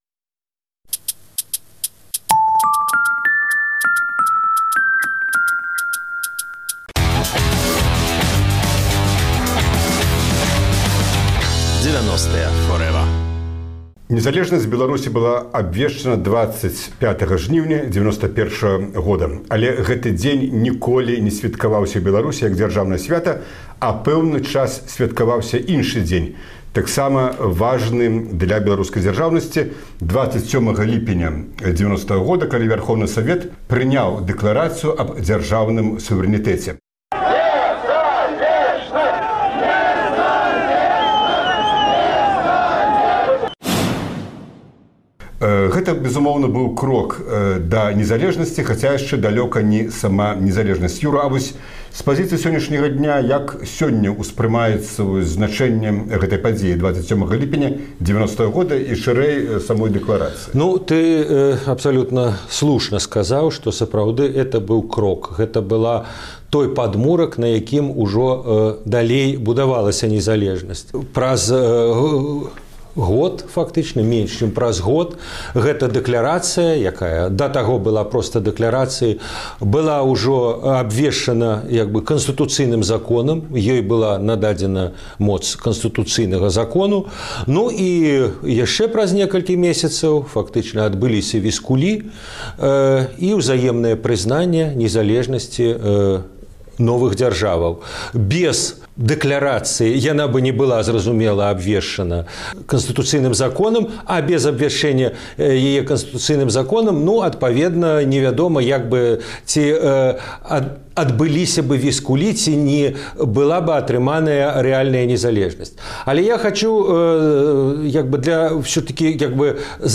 Апошняе інтэрвію Максіма Лужаніна